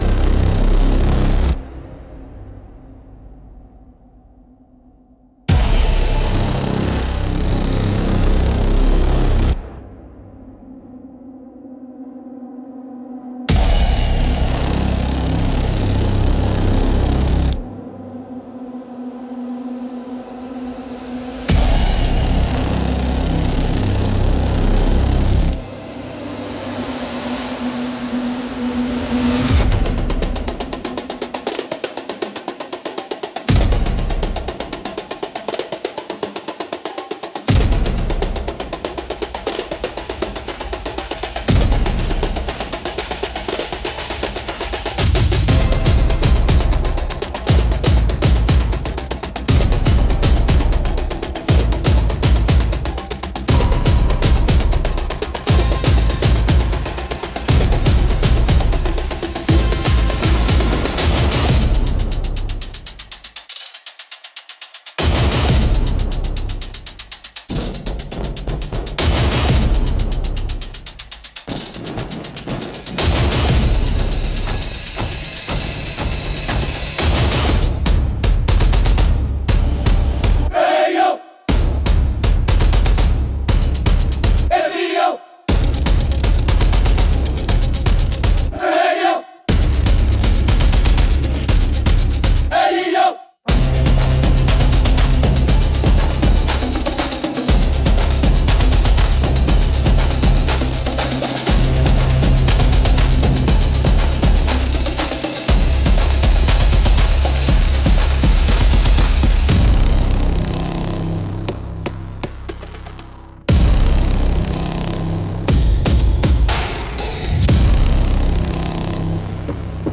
Instrumental - Drums Beneath Neon Skies - 3.01